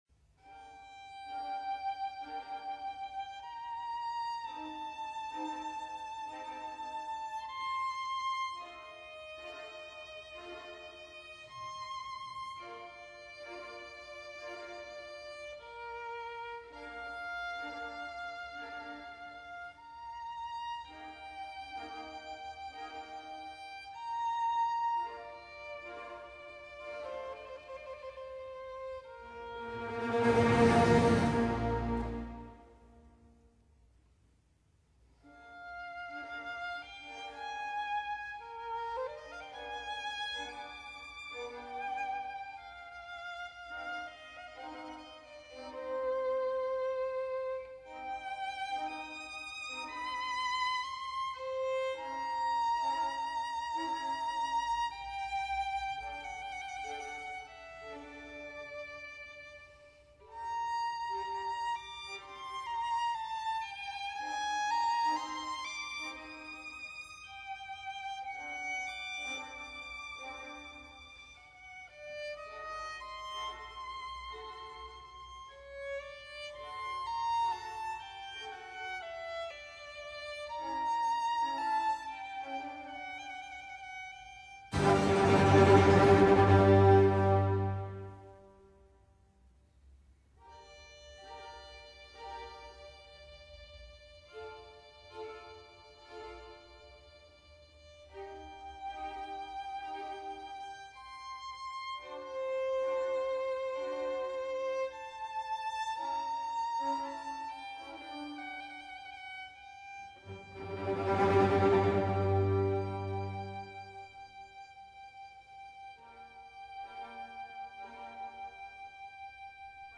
远处雷声所引起的恐惧扰乱了代表牧童的镇静悠缓的独奏小提琴乐句（Presto：全部弦乐器沉重的碎弓），他试图再睡(Adagio)。